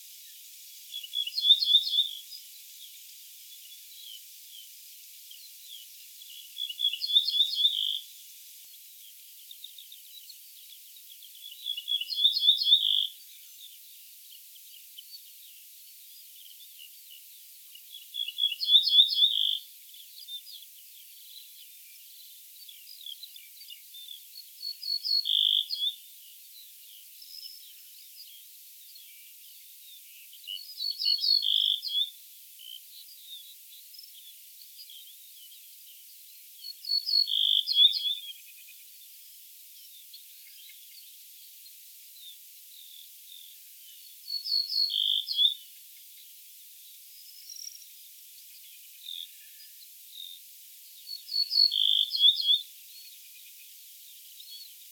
Emberiza hortulana - Ortolan bunting - Ortolano
DATE/TIME: 22/june/2014 (6 a.m.) - IDENTIFICATION AND BEHAVIOUR: One bird (not in sight) sings in an abandoned quarry with steep rocky sides. Zone with steppe, geazed meadows and woods. - POSITION: Somova, Tulcea district, Romania, LAT. N. 45°10'/LONG. E 28°39' - ALTITUDE: 100 m. - VOCALIZATION TYPE: full song. - SEX/AGE: adult male - COMMENT: The audio sample is part of a longer recording that contains an entire bout of song (see spectrogram time axis to check for the cut part). Background: Skylark. - MIC: (WA)